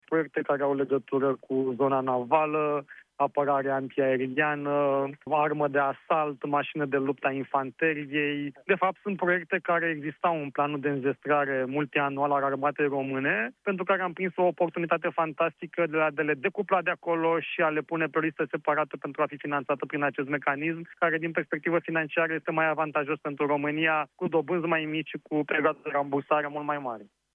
România va cere Comisiei Europene acordul pentru desecretizarea proiectelor ce se vor realiza cu banii primiți prin programul SAFE, pentru înzestrarea Armatei – spune, la Europa FM, ministrul Apărării, Radu Miruță.